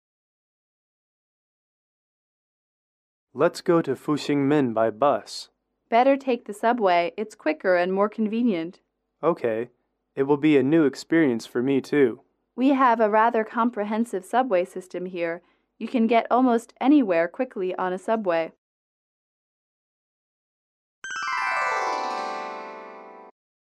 英语主题情景短对话30-1：乘地铁(MP3)